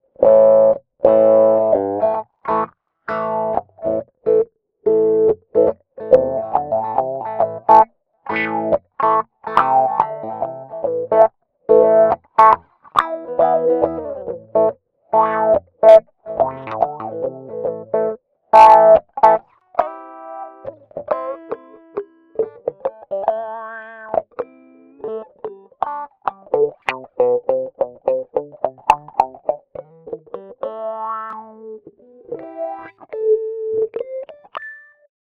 Der Wah-Wah-Effect ist ein klassischer Gitarreneffekt.
Durch Vor- und Zurückwippen des Pedals entsteht ein Sound ähnlich der Modulation, die der menschliche Vokaltrakt hervorruft, was dem Effekt seinen Namen gegeben hat.
Mit Wah-Wah-Simulation bearbeitet: